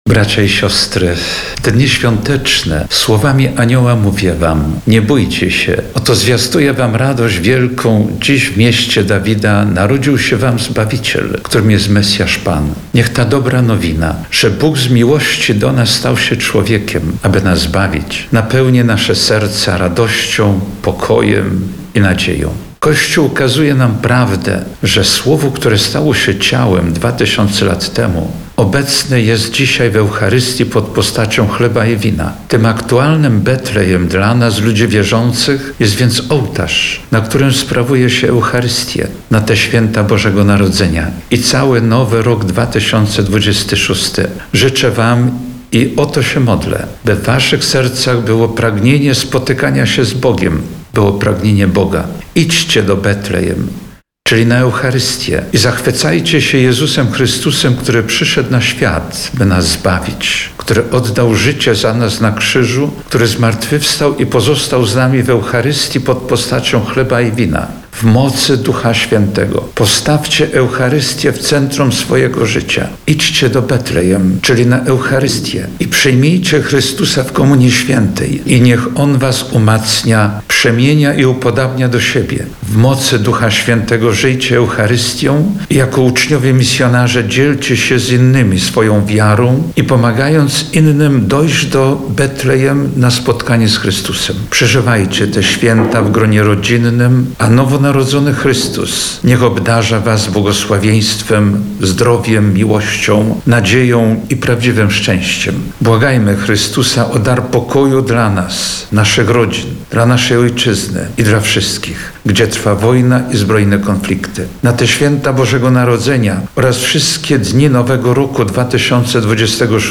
Świąteczne orędzie ordynariusza diecezji ełckiej
Błagajmy Chrystusa o dar pokoju dla naszej Ojczyzny i dla wszystkich, gdzie trwa wojna i zbrojne konflikty – mówi do wiernych w orędziu świątecznym biskup diecezji ełckiej ksiądz Jerzy Mazur.